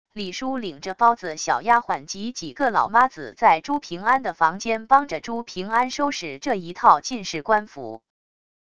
李姝领着包子小丫鬟及几个老妈子在朱平安的房间帮着朱平安收拾这一套进士冠服wav音频生成系统WAV Audio Player